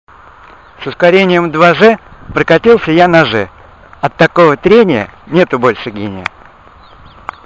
записи своих стихов